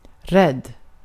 Ääntäminen
IPA: /rɛd/